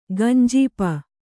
♪ gañjīpa